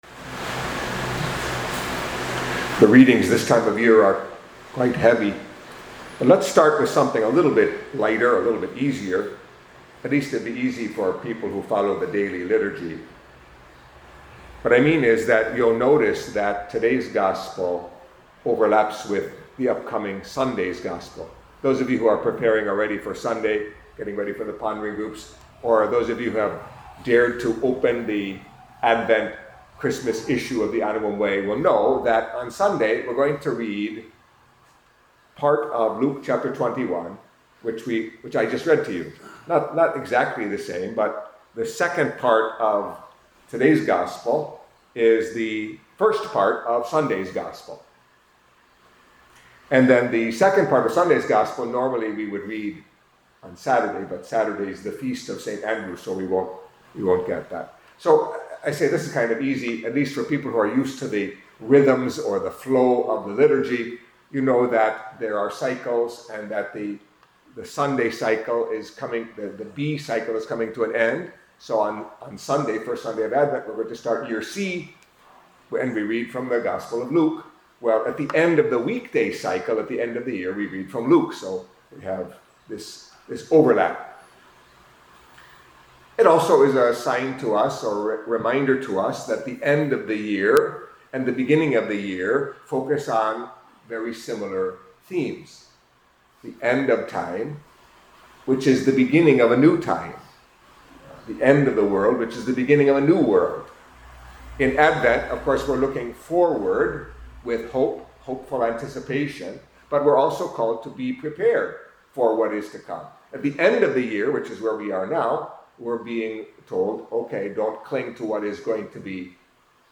Catholic Mass homily for Thursday of the Thirty-Fourth Week in Ordinary Time